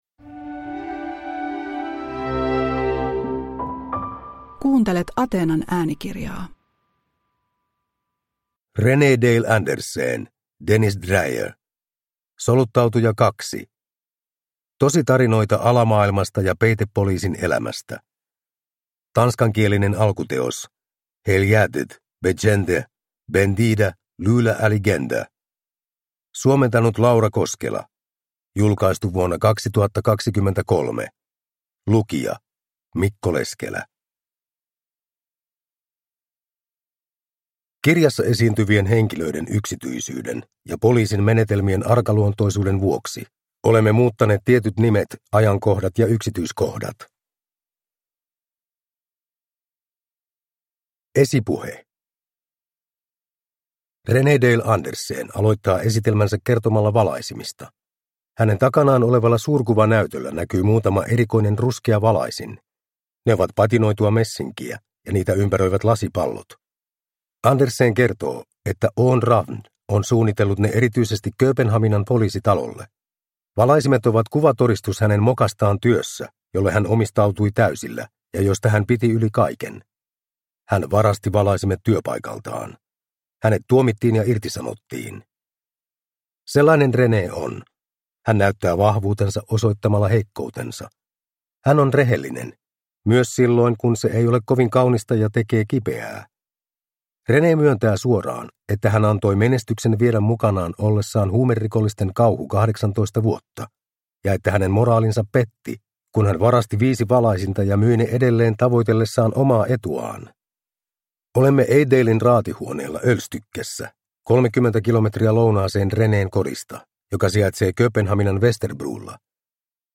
Soluttautuja 2 – Ljudbok – Laddas ner